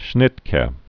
(shnĭtkĕ), Alfred 1934-1998.